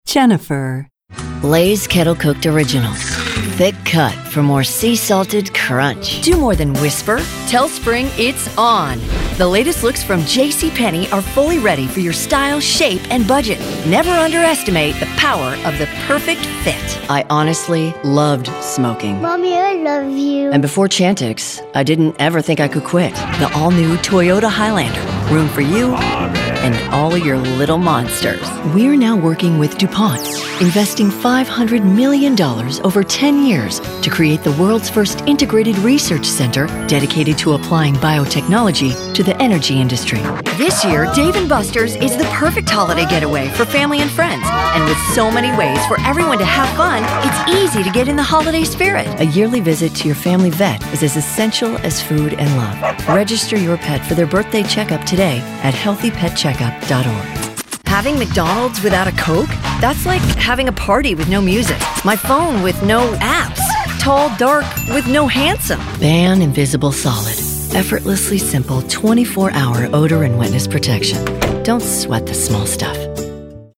She's got a smooth voice with a hint of smokiness that brings warmth and depth to her reads.
Showcase Demo
announcer, anti-announcer, attitude, authoritative, caring, classy, compelling, confident, conversational, corporate, friendly, genuine, high-energy, middle-age, mother, professional, promo, retail, smooth, upbeat, warm